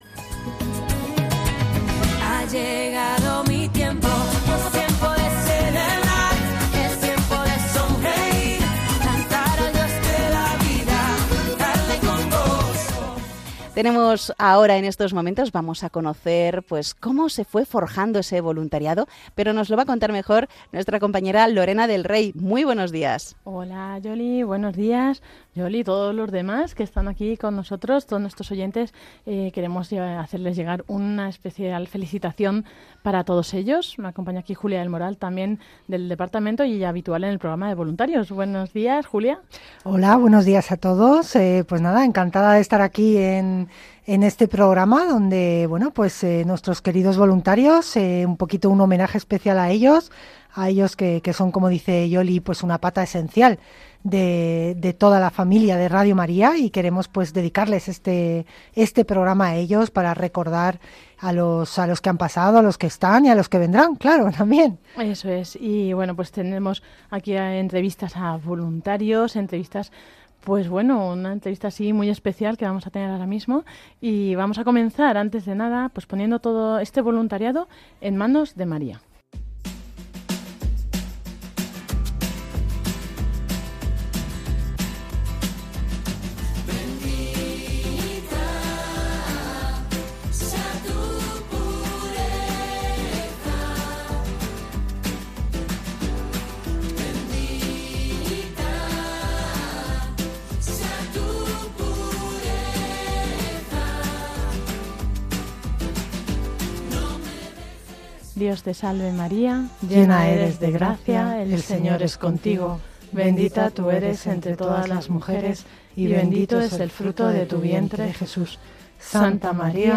Presentació, oració, entrevista